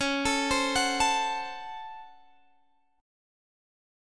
Listen to C#M7sus4#5 strummed